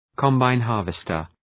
Shkrimi fonetik{‘kɒmbaın,hɑ:rvıstər}